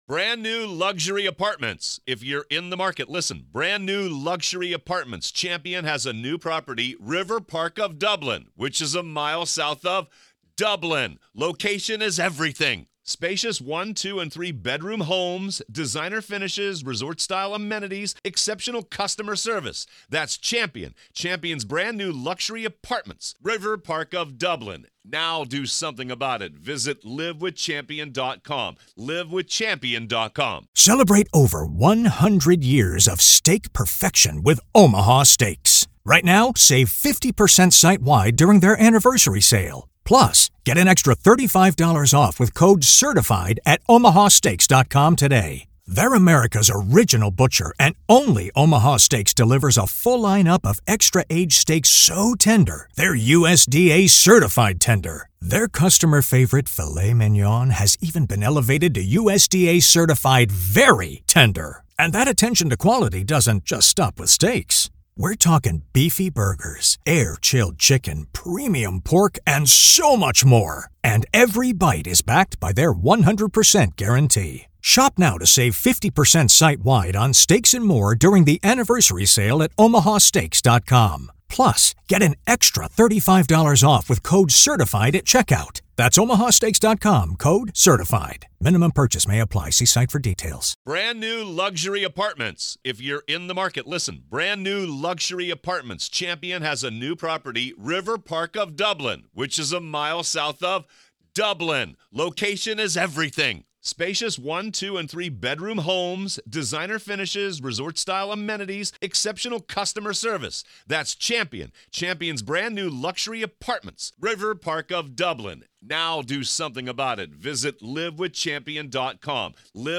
In this rare, emotionally raw conversation, acclaimed journalist Howard Blum opens up about the three burning questions he’d ask Kohberger if given the chance — questions that cut to the core of this horrific crime: Why did you want to kill?